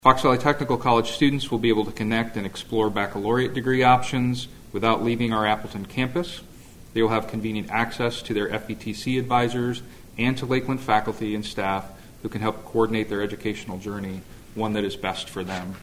That announcement came during a morning news conference.